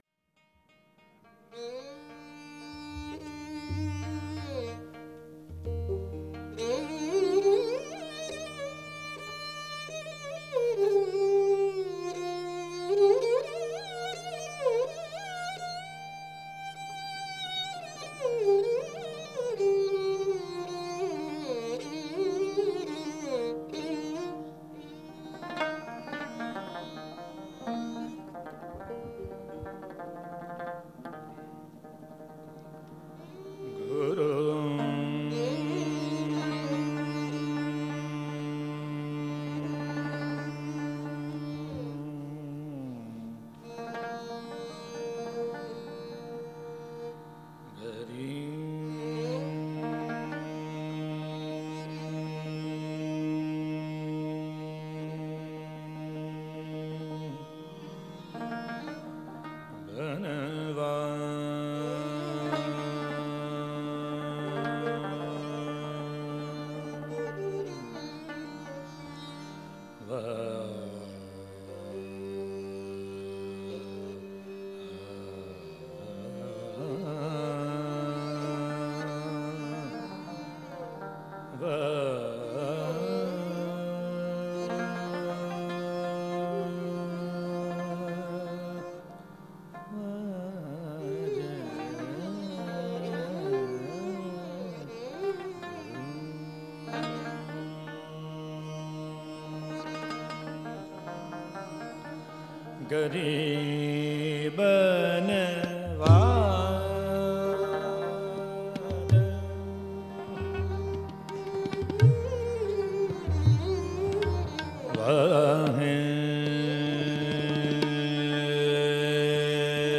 Raag Malshri